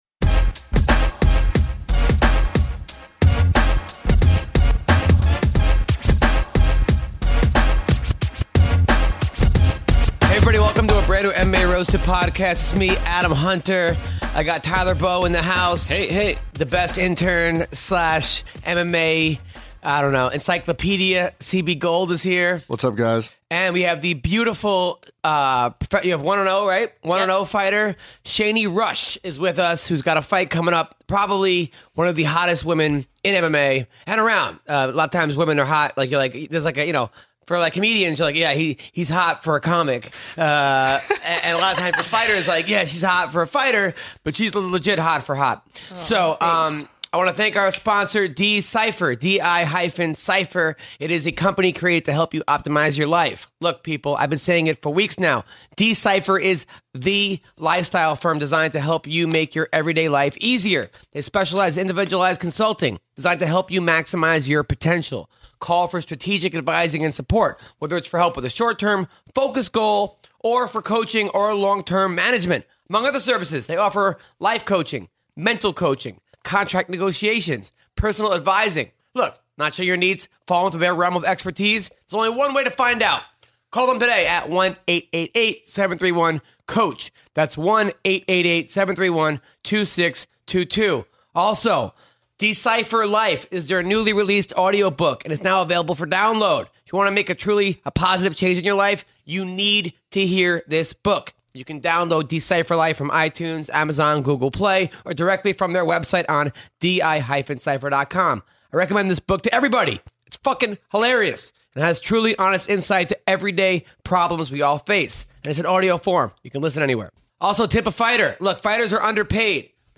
joined in studio
press conference